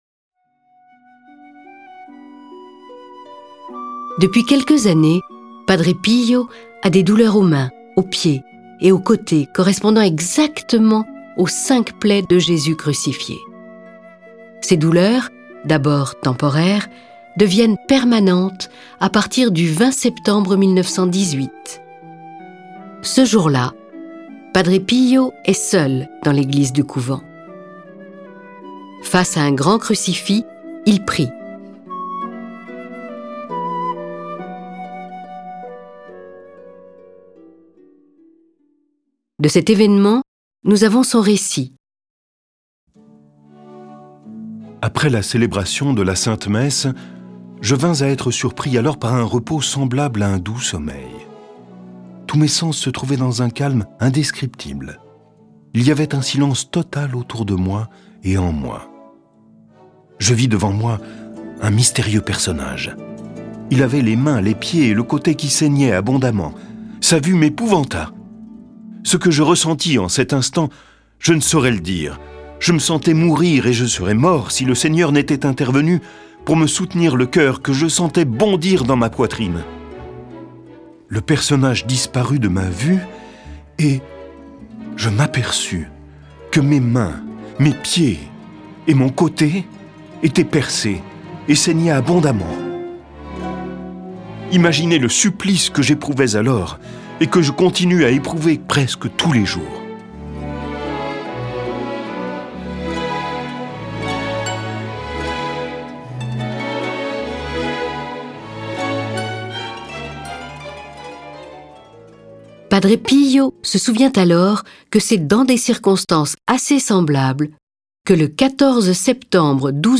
Découvrez la vie édifiante de celui qui eut, dès l'âge de quatre ans, les manifestations du démon et reçut pendant cinquante ans les stigmates du Seigneur. Cette version sonore de sa vie est animée par dix voix et accompagnée de plus de trente morceaux de musique classique.